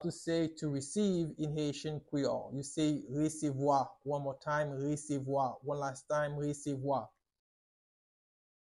Pronunciation:
23.How-to-say-To-receive-in-Haitian-Creole-Resevwa-with-pronunciation.mp3